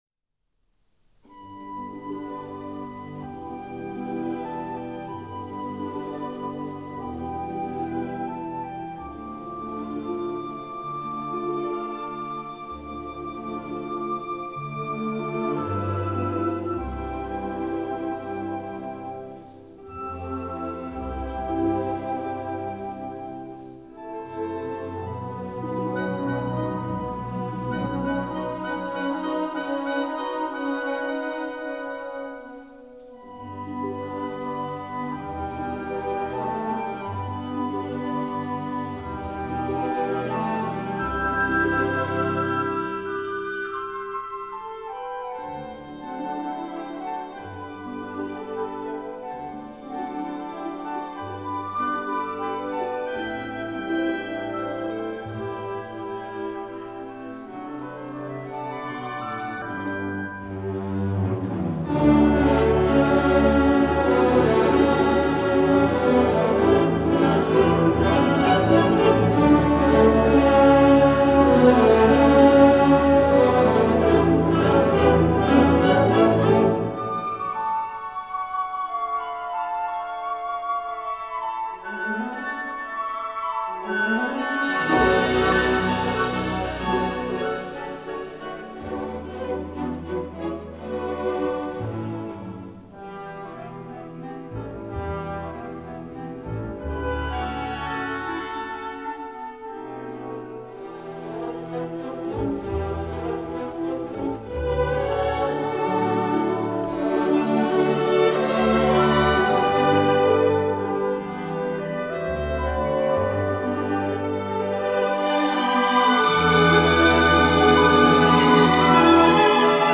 Orquesta